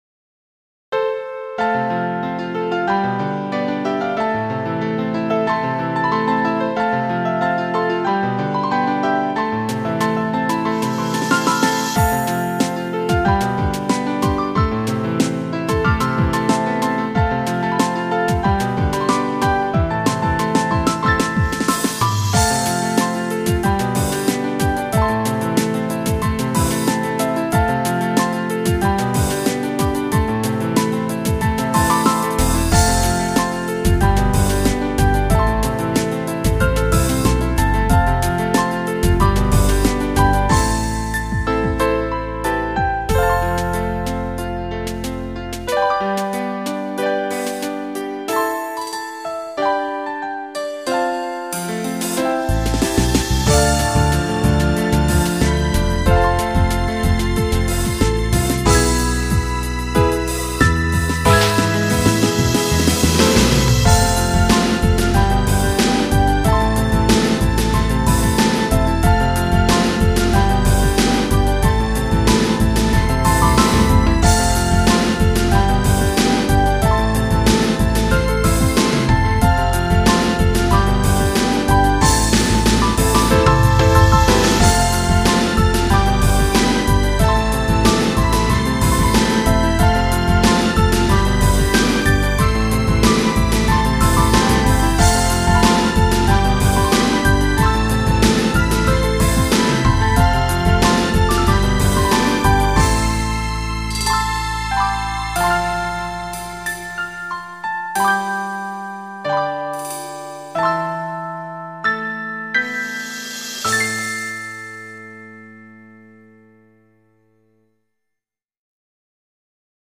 Healing
ピアノ、ベース、ドラムをメインとした珍しい楽器構成になっています。
息抜き程度に作っていたものですが、シンプルにまとまりました。
途中でスネアの音が変わるのもちょっとしたアクセントですね。